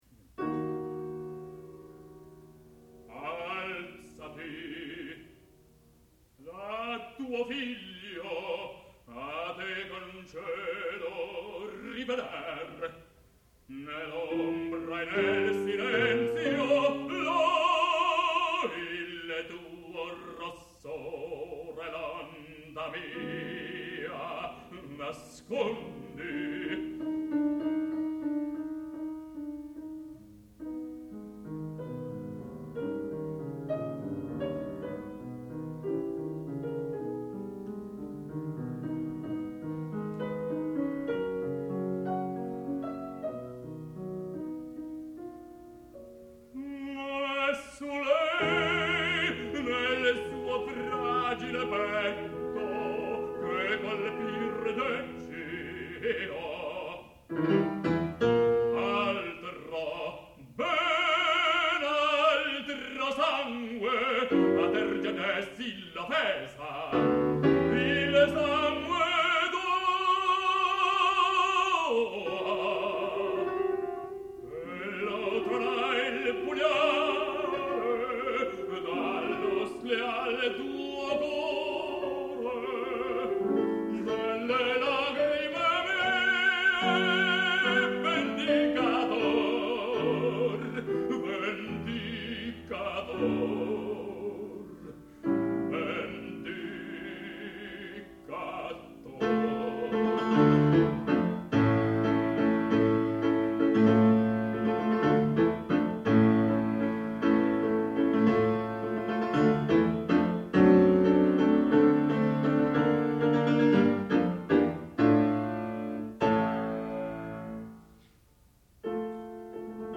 sound recording-musical
classical music
Knut Skram, baritone and Eva Knardahl, piano (performer).